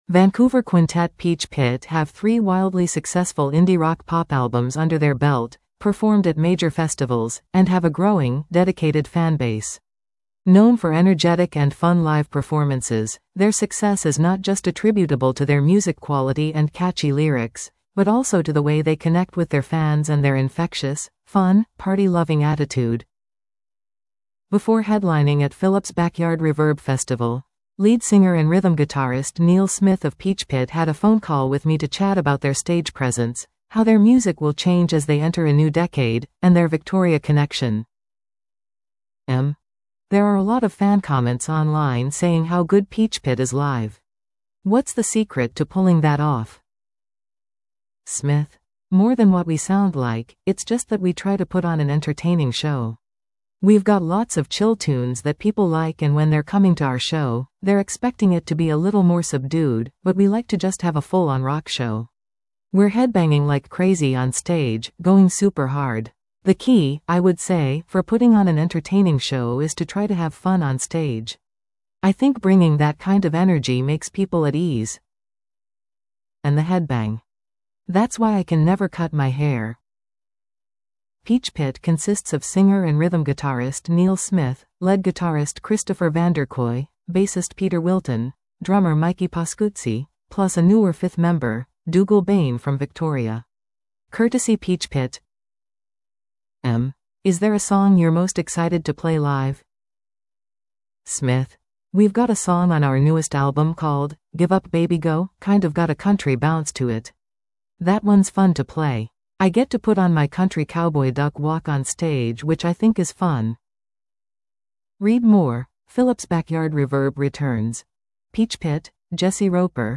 Interview: Peach Pit